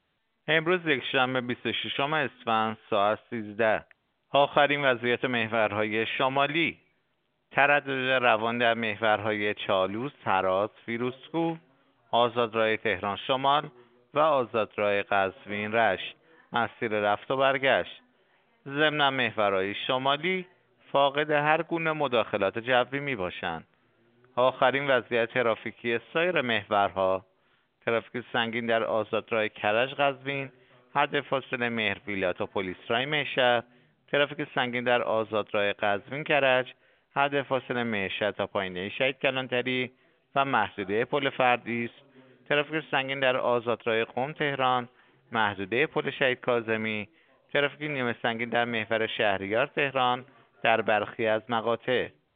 گزارش رادیو اینترنتی از آخرین وضعیت ترافیکی جاده‌ها ساعت ۱۳ بیست و ششم اسفند؛